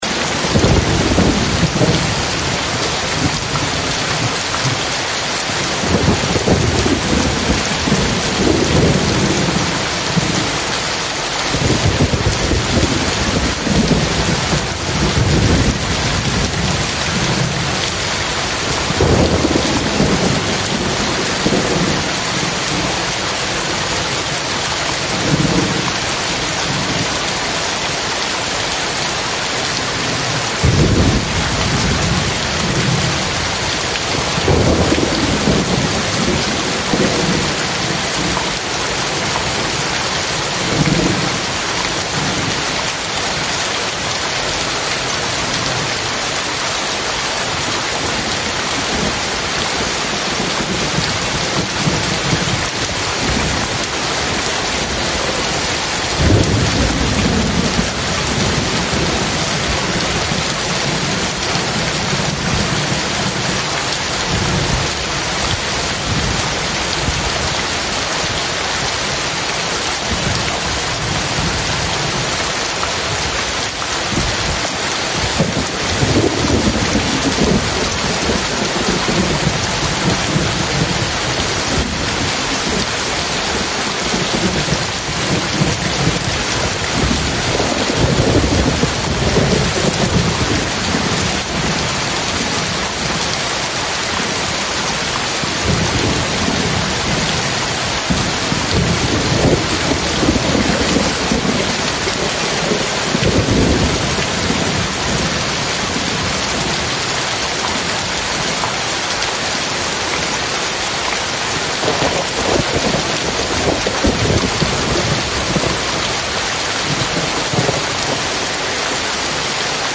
Звуки дождя по крыше
Звуки мощного дождя, ливня: